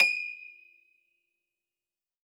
53q-pno23-D5.wav